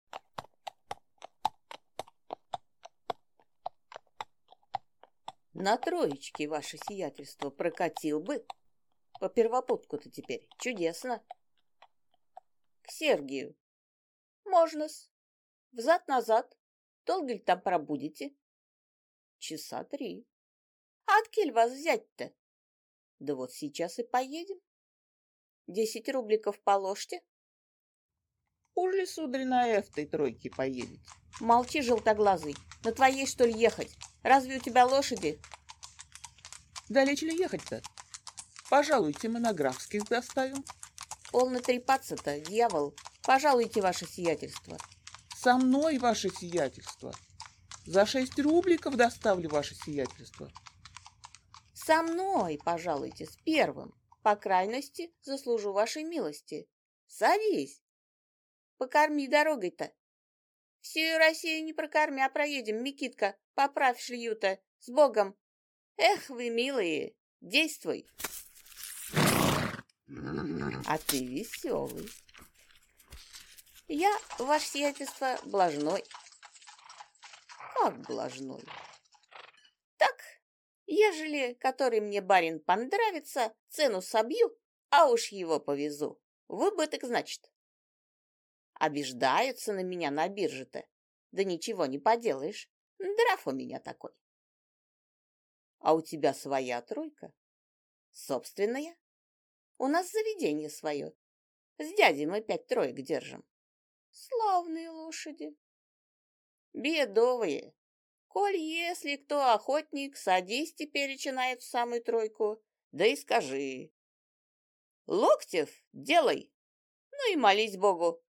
Аудиокнига С легкой руки | Библиотека аудиокниг